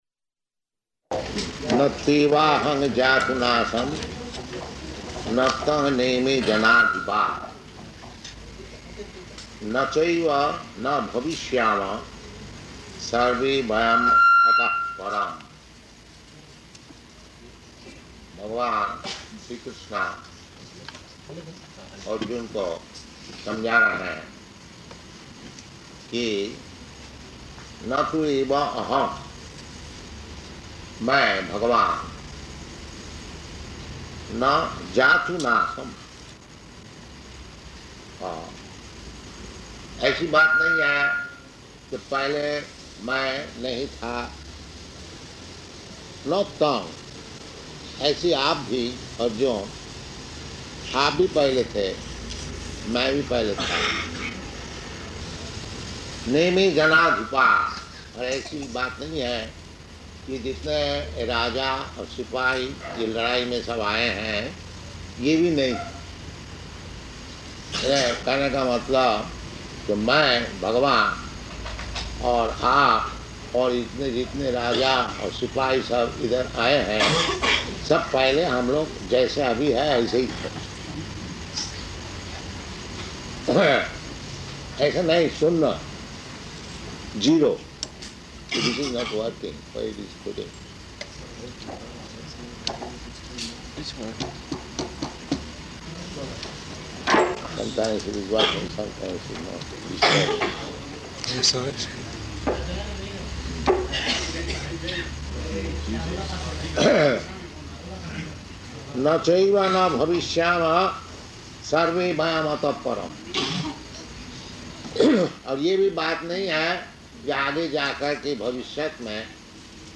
Location: Jakarta